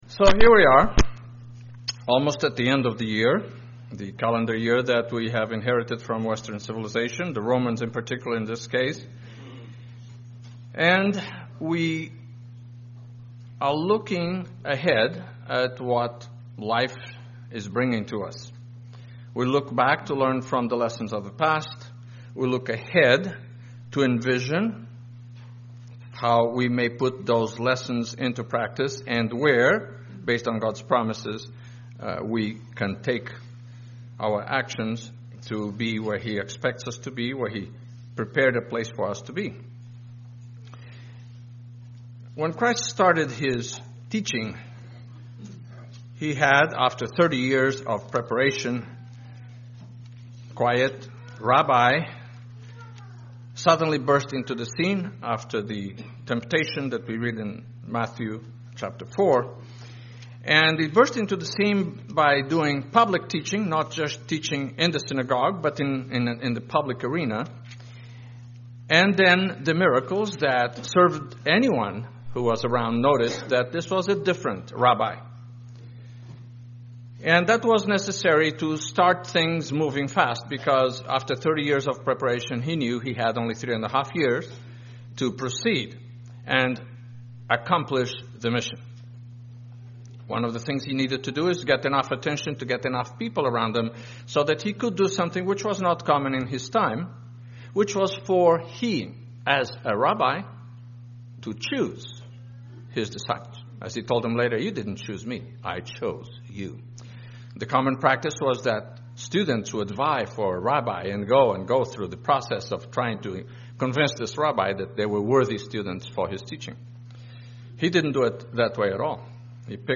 Given in San Francisco Bay Area, CA Petaluma, CA